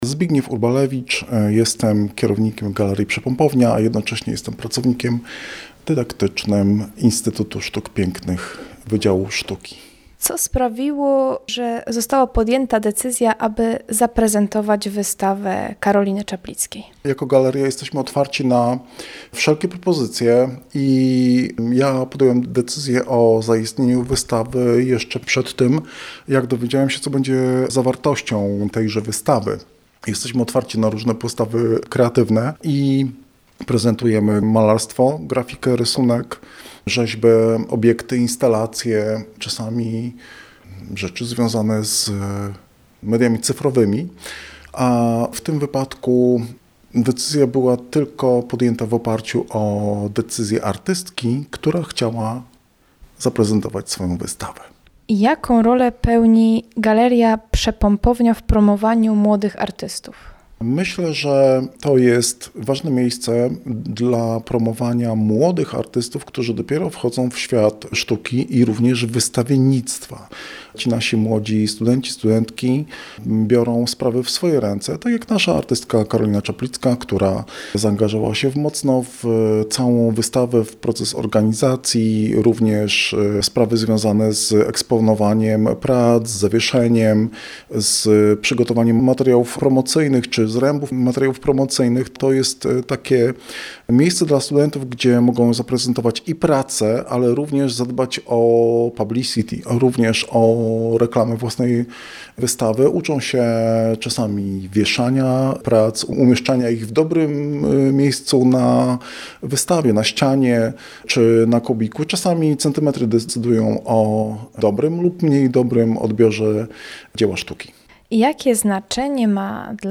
Wernisaż odbył się we wtorek 6 maja.